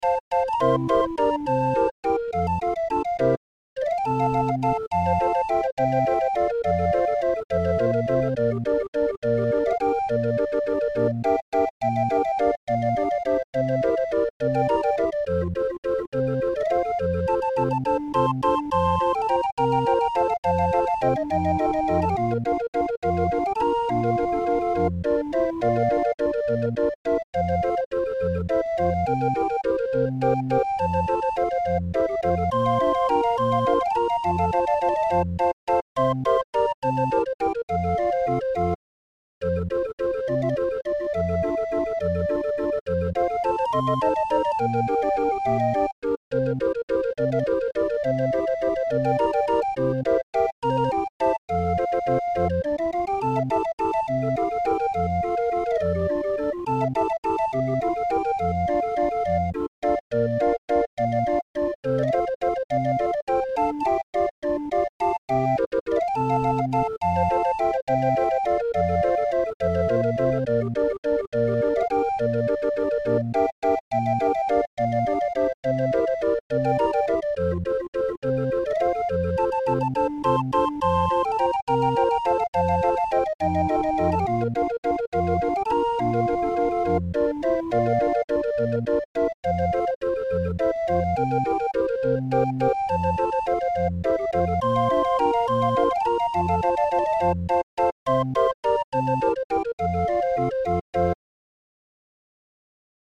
Muziekrol voor Raffin 20-er